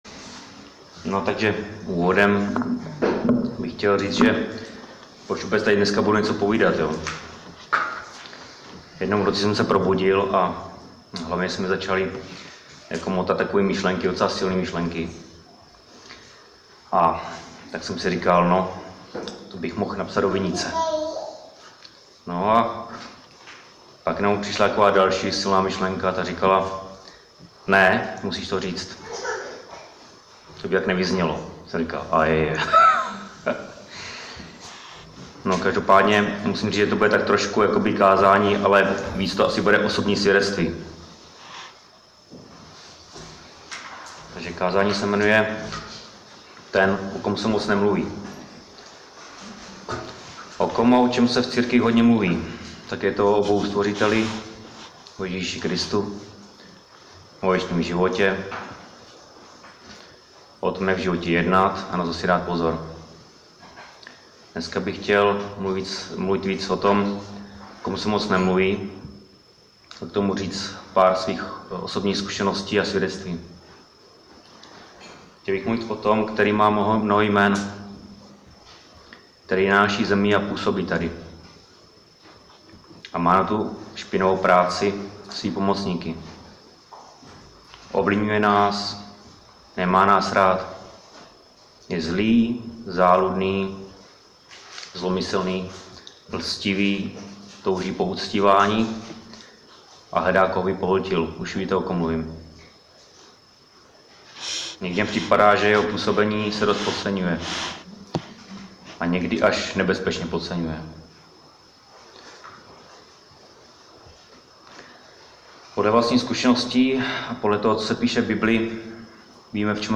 Křesťanské společenství Jičín - Kázání 29.4.2018